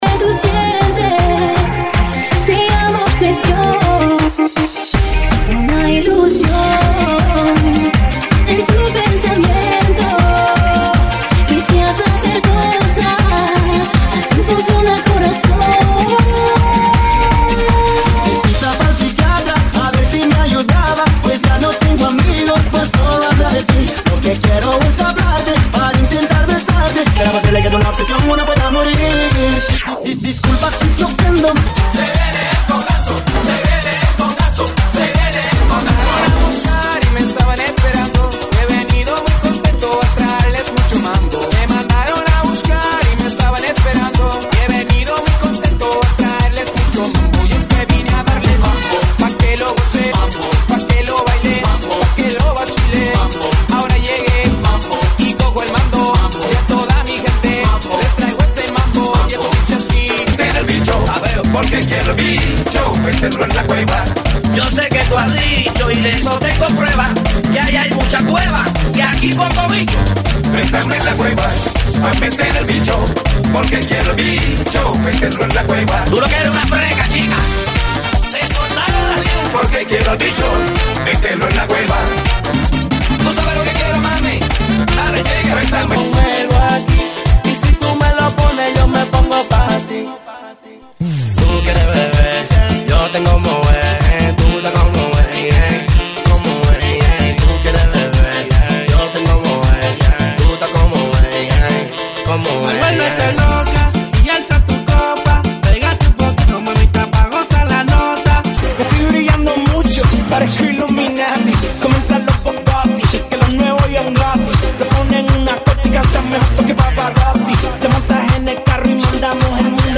GENERO: MAMBO – MERENGUE
MAMBO MERENGUE FAST